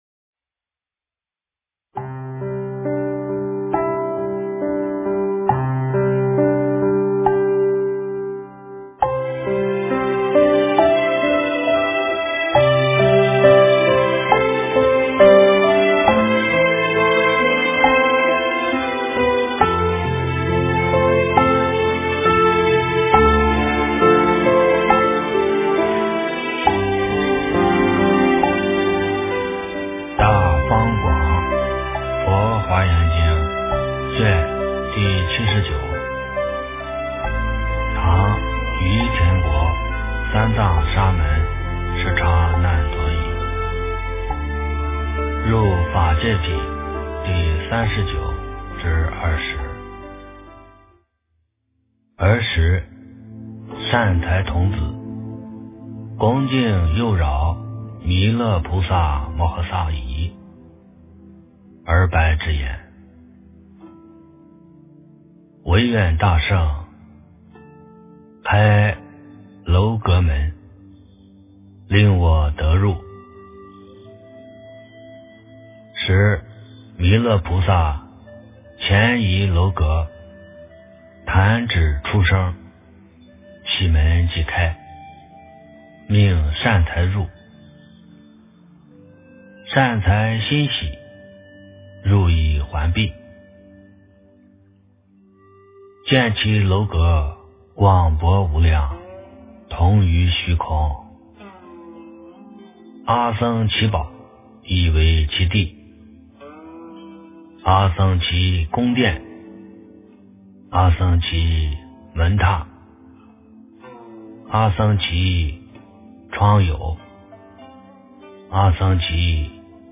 诵经
佛音 诵经 佛教音乐 返回列表 上一篇： 地藏菩萨本愿经 下一篇： 大势至菩萨念佛圆通章 相关文章 宗喀巴上师赞--观自在密宗咒语 宗喀巴上师赞--观自在密宗咒语...